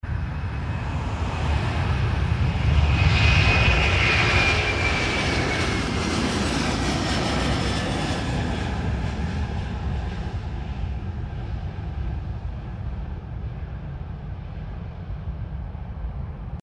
Airbus A330-202 LV-FVI Aerolíneas Argentinas